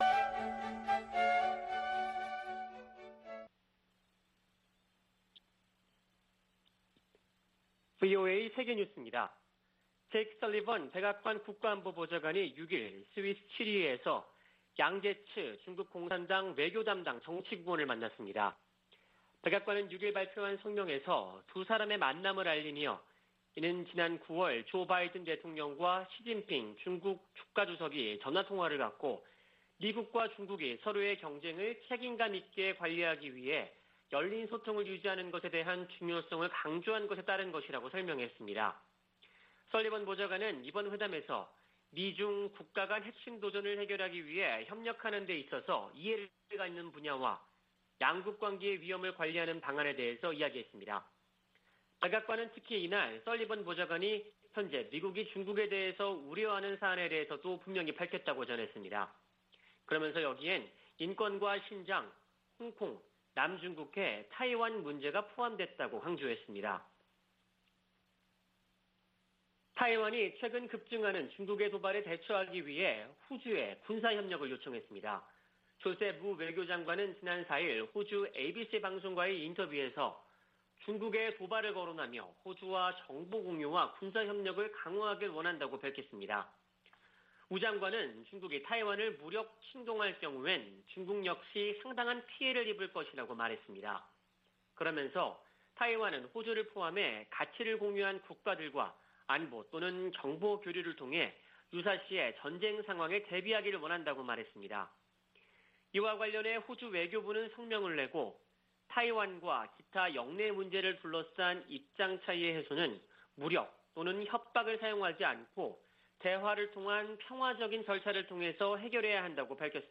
VOA 한국어 아침 뉴스 프로그램 '워싱턴 뉴스 광장' 2021년 10월 7일 방송입니다. 미 국무부는 유엔 안전보장이사회 전문가패널 중간보고서 발표와 관련, 북한의 계속되는 불법 활동에 우려를 나타냈습니다. 유럽연합과 덴마크 등이 유엔에서, 북한의 핵과 탄도미사일 프로그램이 역내 안보 위협이라고 지적했습니다. 최종건 한교 외교부 1차관은 북한이 당장이라도 남북 군사공동위원회에 나와 대화할 것을 촉구했습니다.